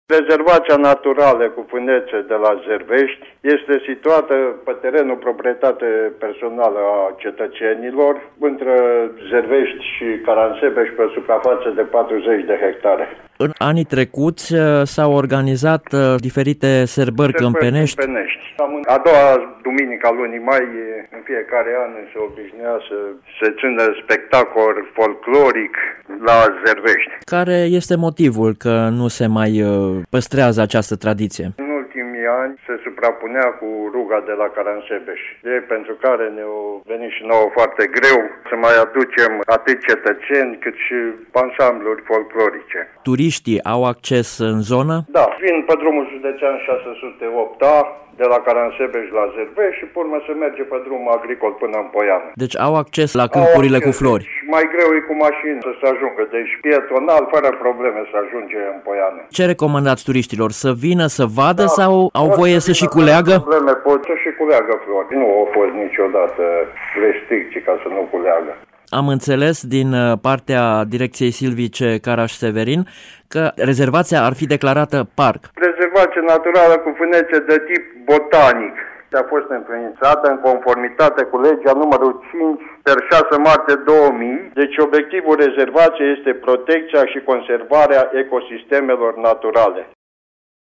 viceprimarul comunei Turnu Ruieni, Nicolae Bălu